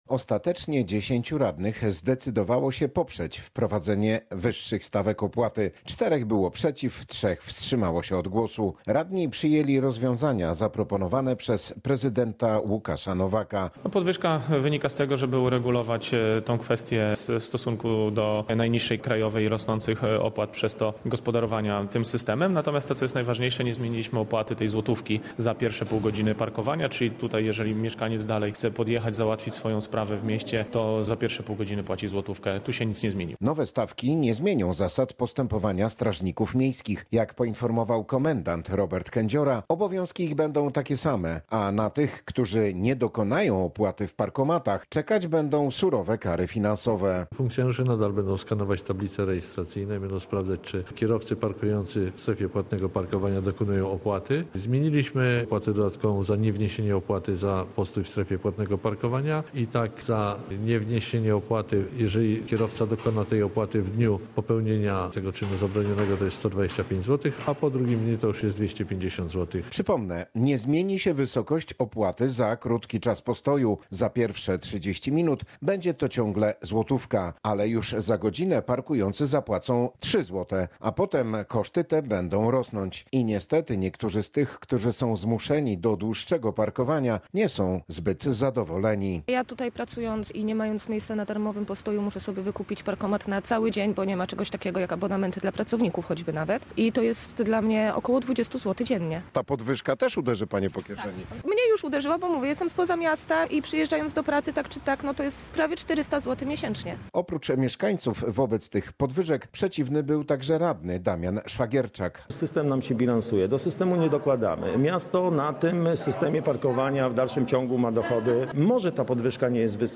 -Powodem podwyżki jest wzrost kosztów obsługi parkomatów – wyjaśnia prezydent Tarnobrzega Łukasz Nowak.
Relacja